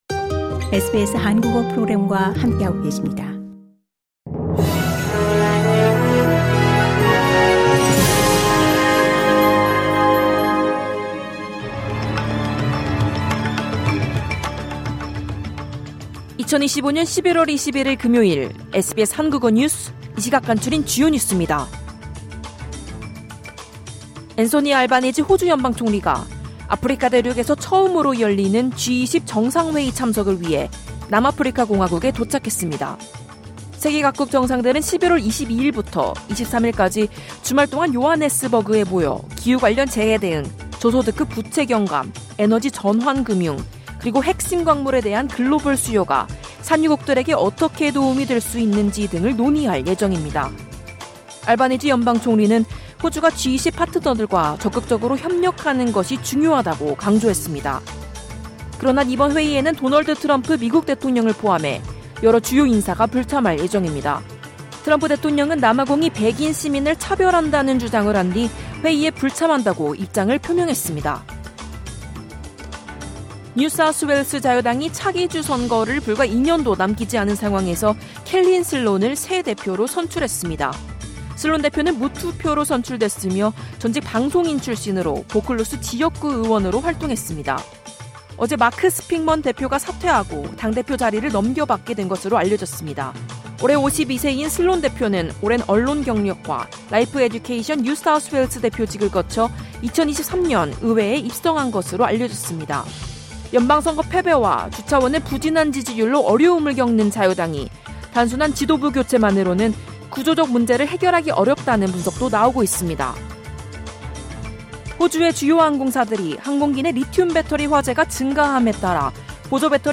2025년 11월 21일 금요일의 호주 뉴스를 짧고 간단하게 한국어로 정리해 드립니다.